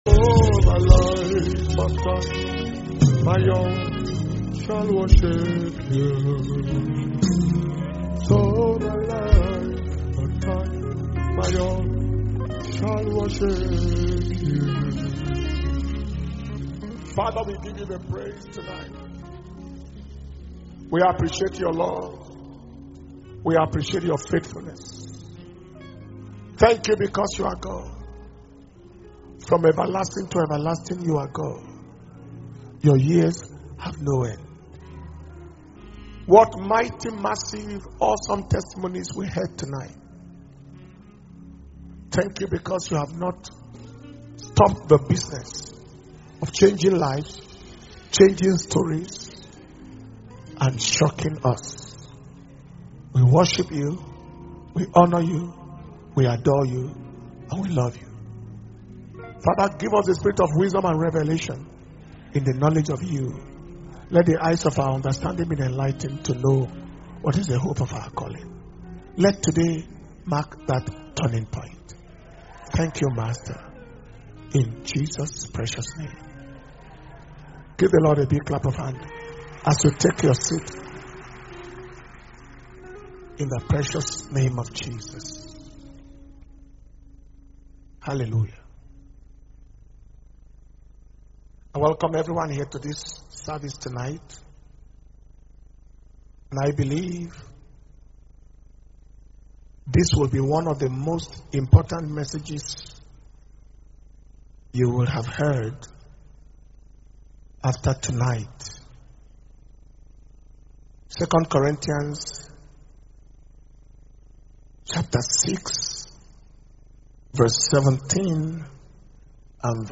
Power Communion Service – Wednesday, 22nd September 2021